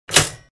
Toaster Pop Up
Toaster Pop Up.wav